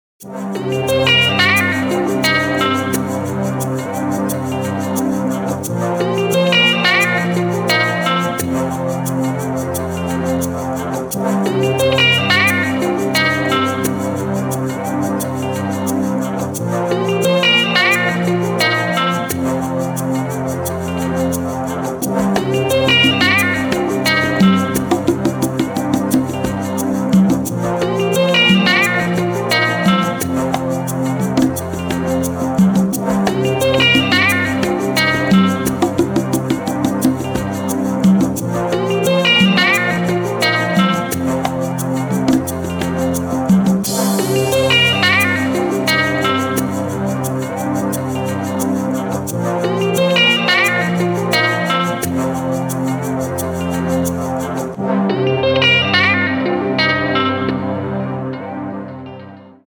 • Качество: 256, Stereo
гитара
Electronic
спокойные
без слов
Downtempo
электрогитара
маракасы
ударные
барабаны